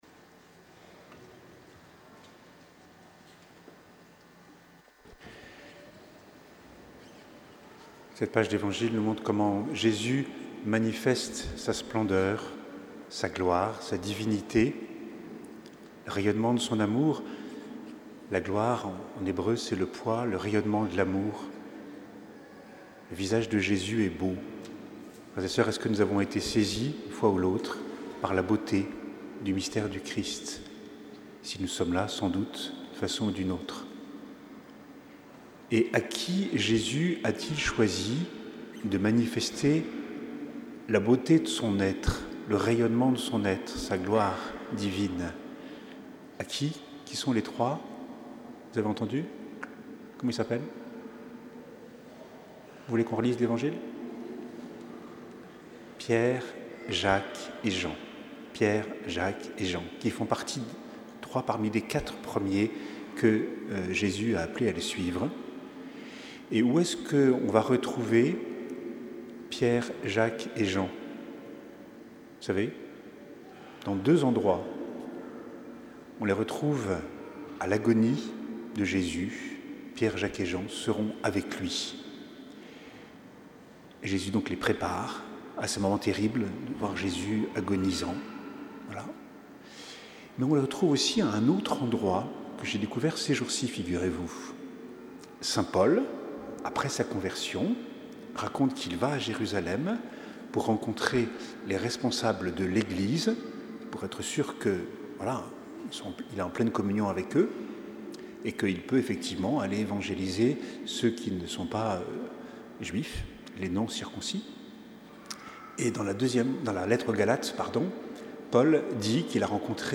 Homélie du 2ème dimanche de Carême - 1er mars 2026 • Paroisse Saint-Nizier